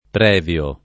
pr$vLo] agg.; pl. m. ‑vi (raro, alla lat., -vii) — usato perlopiù in costruzioni assolute (burocr.), solo nel sing. e sempre anteposto al nome: procedere previa autorizzazione; riconosciuto previo esame